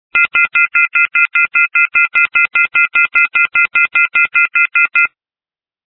Re: [asterisk-users] off-hook warning tone
> > the loud "put the damn phone back on the hook" noise.
the first part is a mixture of 4 tones (1400+2060+2450+2600) and maybe